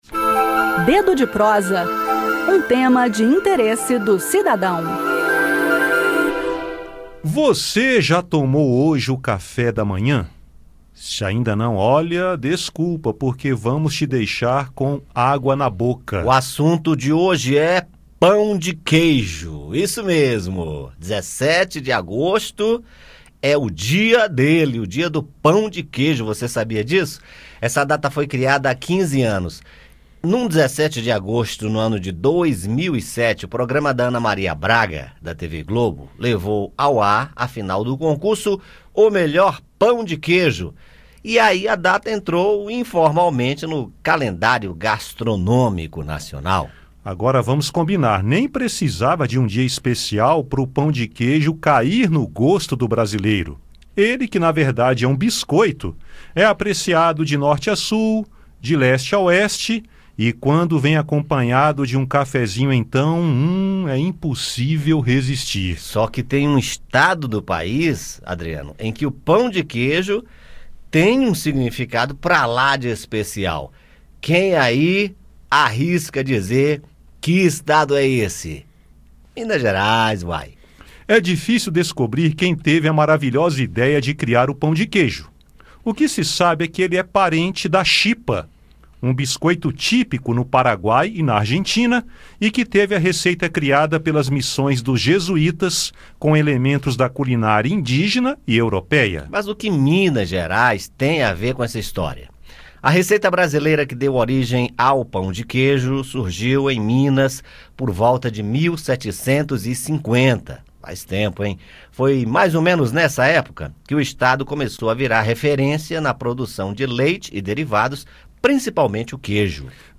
No bate-papo, confira um pouco da história dessa iguaria, apreciada não apenas no Brasil, mas que ganhou a gastronomia internacional, e confira a receita do tradicional e melhor pão de queijo do país.